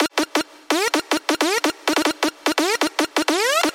House Sounds » 025 House StudioAmbient
描述：studio ambient sound
标签： room studio house
声道立体声